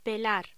Locución: Pelar